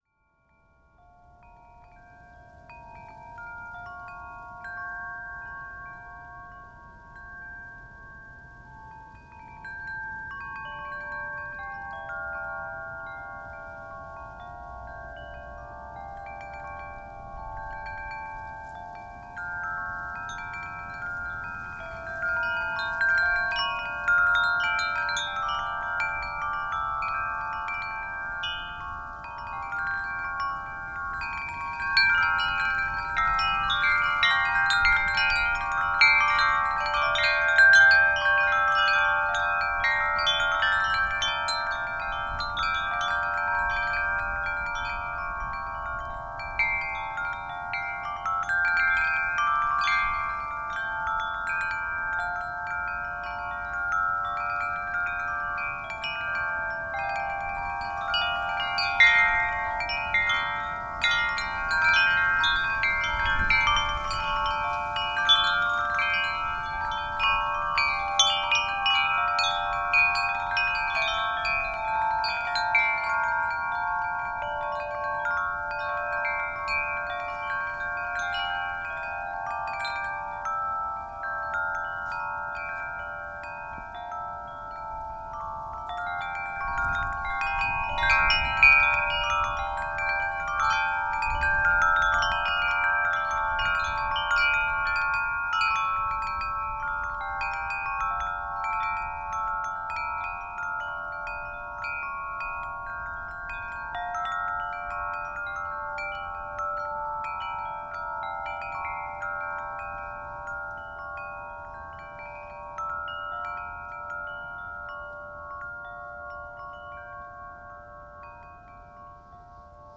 Wind Chimes, Teign Gorge near Castle Drogo - Gypsy Soprano + Mezzo - excerpt
Castle-Drogo chimes Devon England field-recording Gypsy mezzo Music-of-the-Spheres sound effect free sound royalty free Music